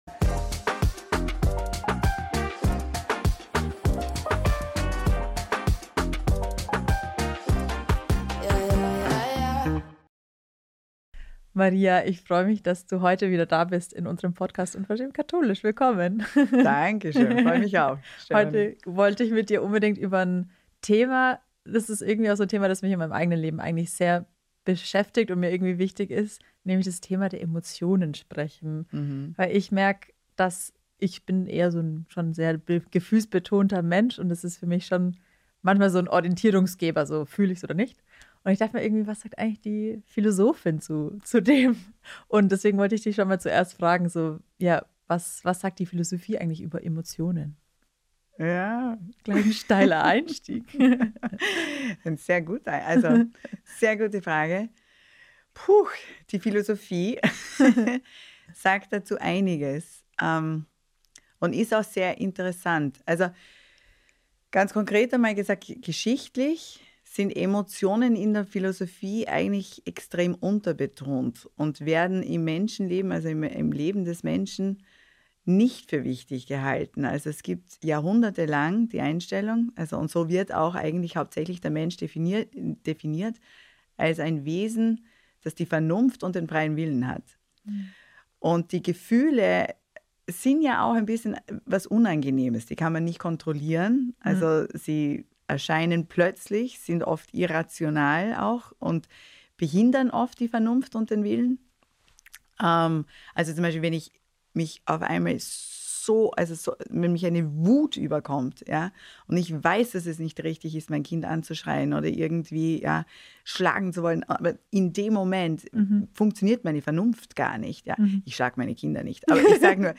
In dieser Folge sprechen wir mit ihr über Emotionen: Was sagt die Philosophie dazu?